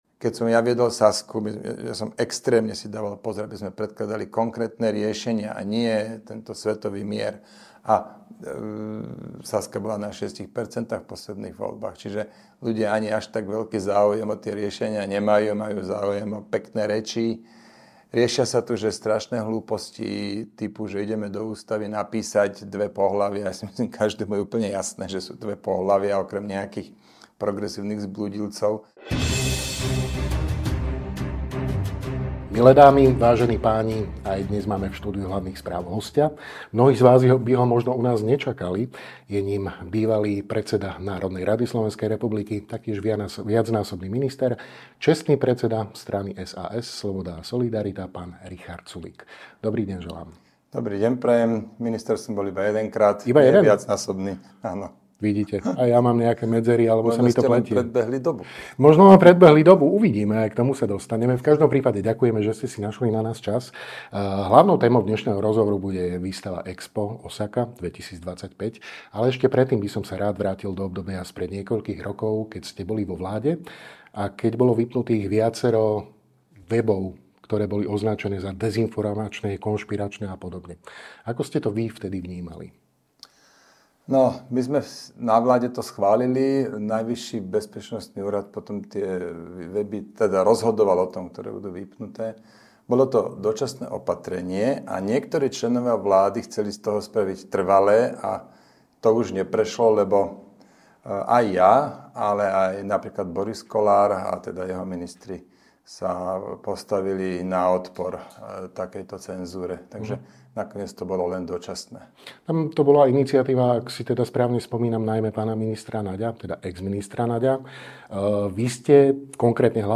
Dozviete sa vo videorozhovore s čestným predsedom SaS, Ing. Richardom Sulíkom.